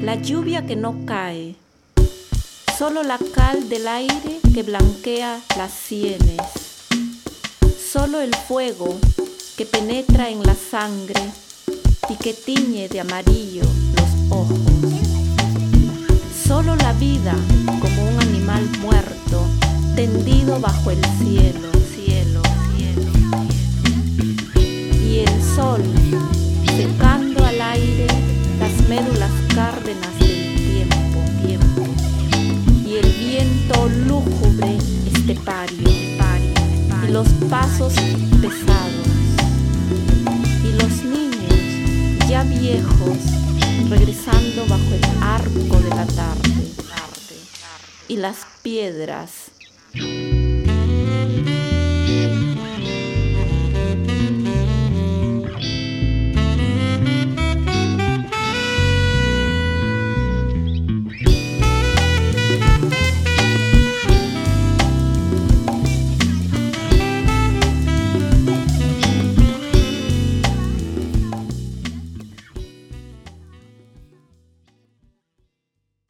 jazzy/dubby